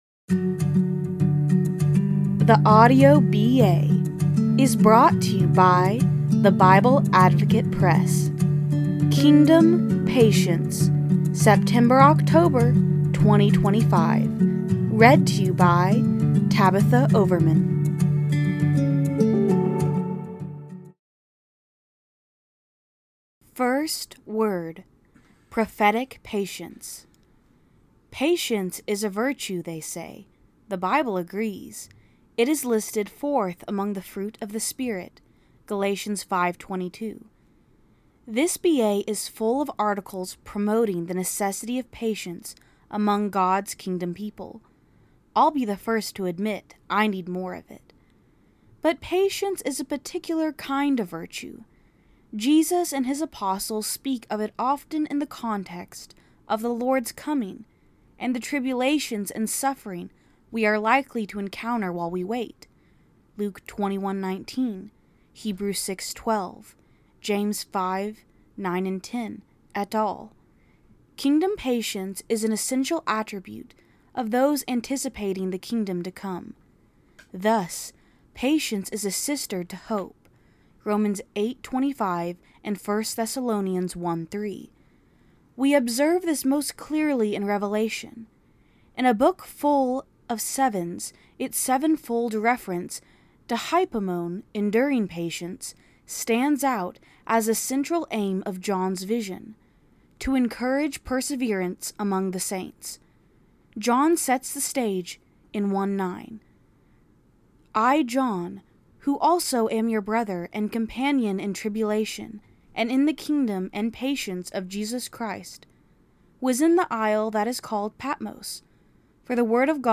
Listen to the September-October 2025 issue of the Bible Advocate magazine. Title: “Kingdom Patience”. Part 5 of the 2025 “Kingdom People” series.